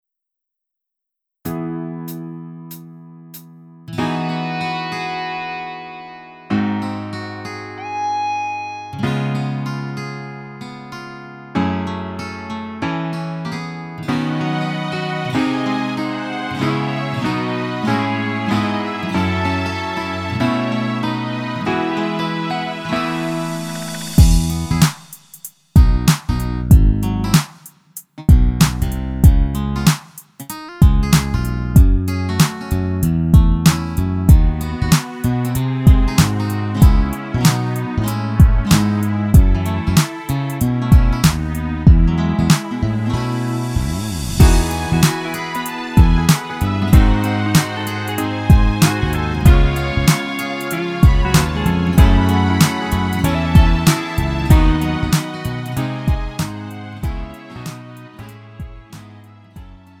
음정 원키 3:14
장르 가요 구분 Lite MR